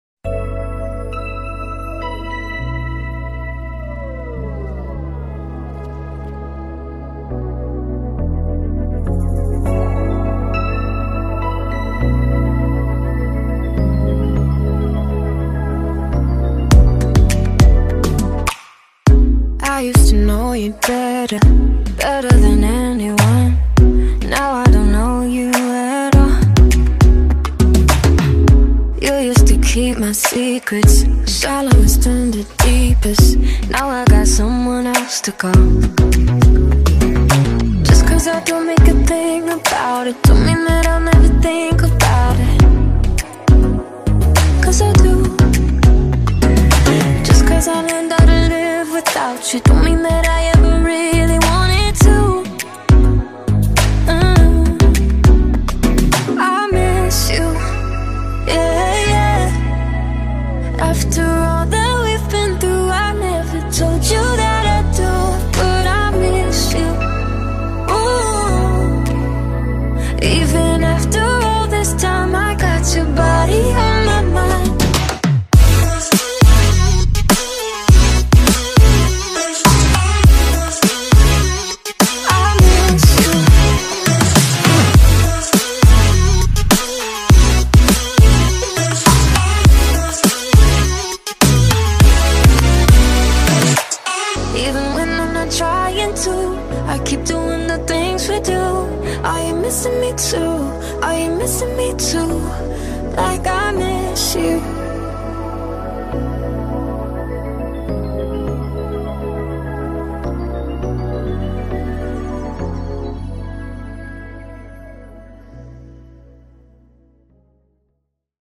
BPM102